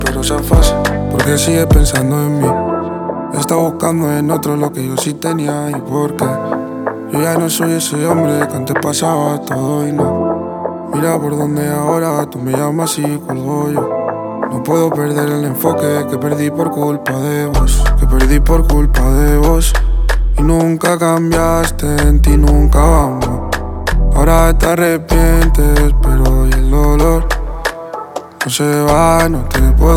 Жанр: Латино
Urbano latino, Latin